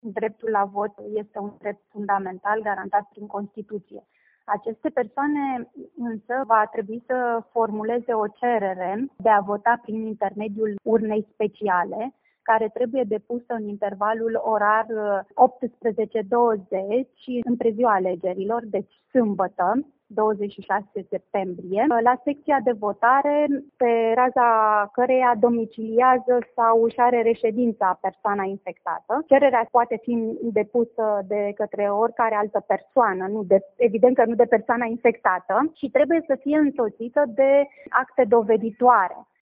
Președintele Biroului Electoral Județean Timiș, Beatrice Ivancu, a explicat condițiile în care poate vota o persoană aflată în spital sau izolată la domiciliu din cauza infectării cu coronavirus: